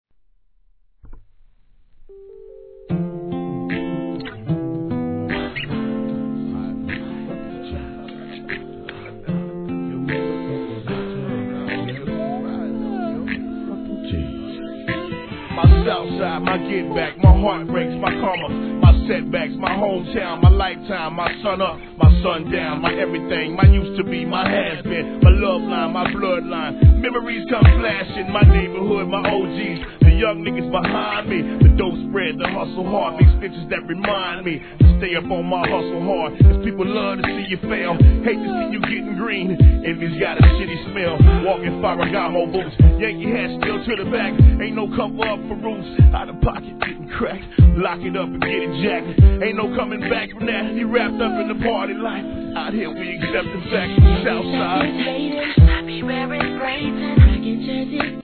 HIP HOP/R&B
心にグッと響くMID TUNE!!!